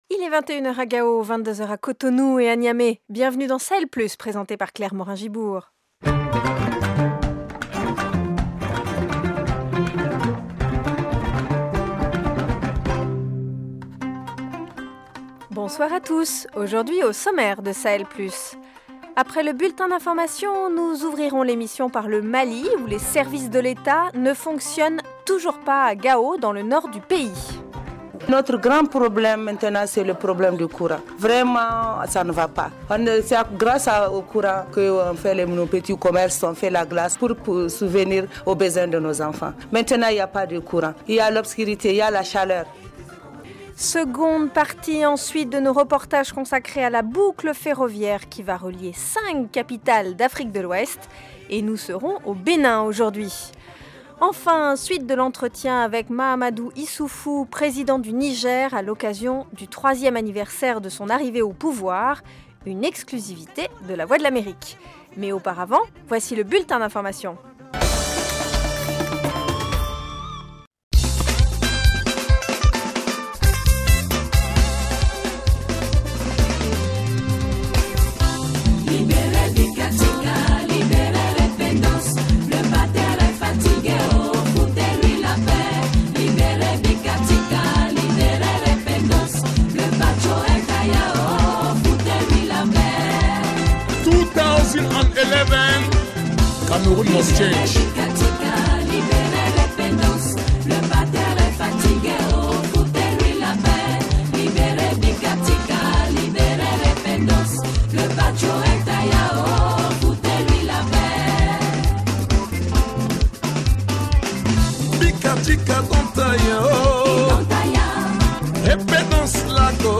Dossier : détour par Cotonou au Benin où la grande boucle ferroviaire de l’Afrique de l’Ouest a été inaugurée cette semaine. African Voice : 2ème partie de l’entretien exclusif avec Mahamadou Issoufou, président du Niger à l’occasion du 3ème anniversaire de son arrivée au pouvoir.